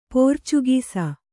♪ pōrcugīs